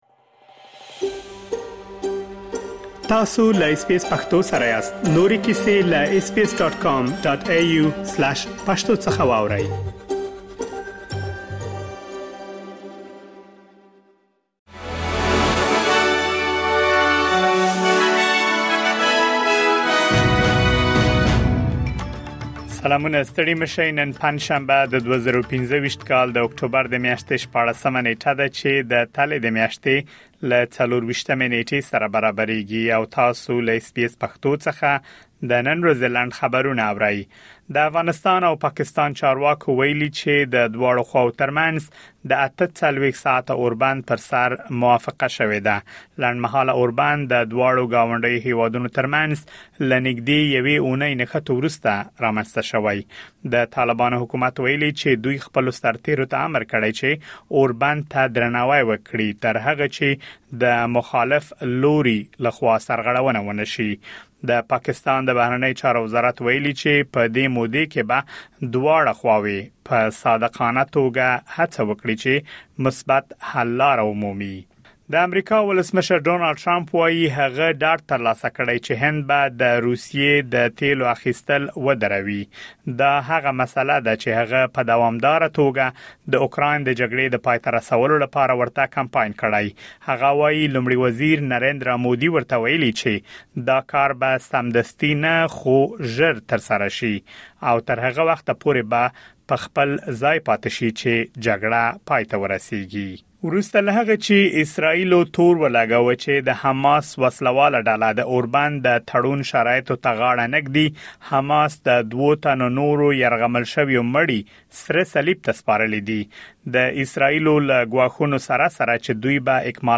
د اس بي اس پښتو د نن ورځې لنډ خبرونه |۱۶ اکټوبر ۲۰۲۵